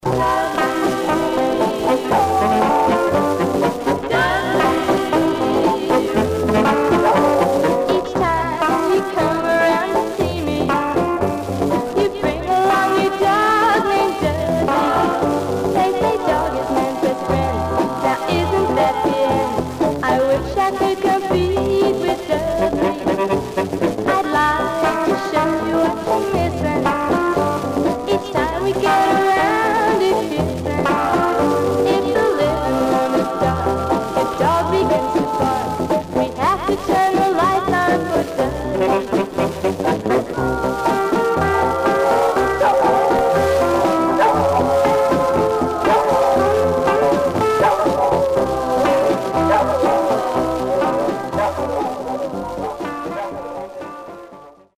Surface noise/wear
Mono
White Teen Girl Groups